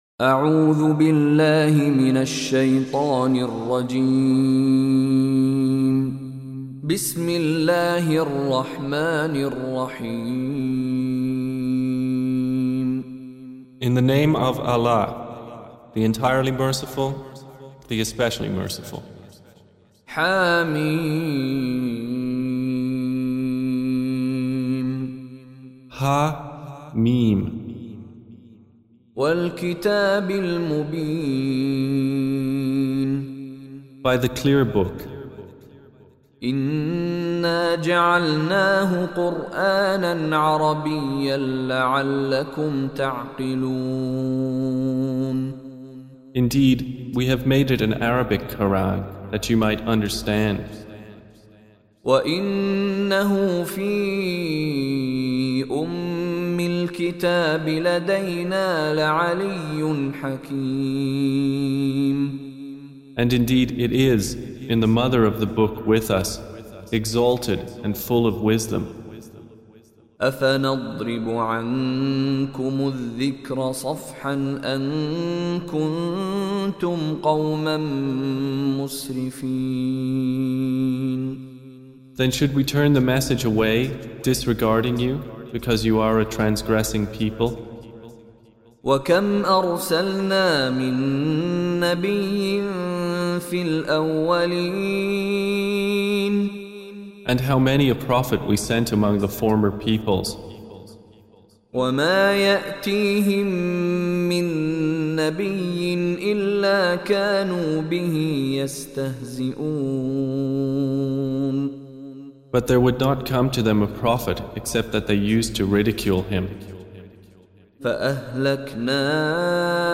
Audio Quran Tarjuman Translation Recitation
Surah Repeating تكرار السورة Download Surah حمّل السورة Reciting Mutarjamah Translation Audio for 43. Surah Az-Zukhruf سورة الزخرف N.B *Surah Includes Al-Basmalah Reciters Sequents تتابع التلاوات Reciters Repeats تكرار التلاوات